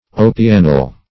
opianyl - definition of opianyl - synonyms, pronunciation, spelling from Free Dictionary Search Result for " opianyl" : The Collaborative International Dictionary of English v.0.48: Opianyl \O"pi*a*nyl\, n. [Opianic + -yl.]